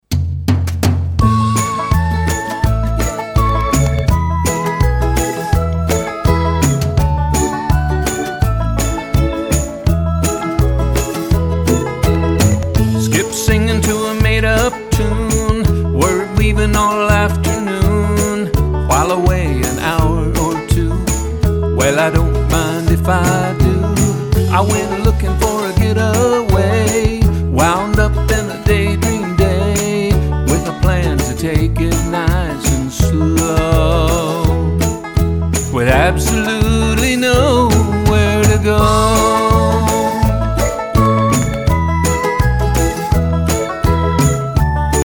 clever, cheeky, laugh-out-loud funny songs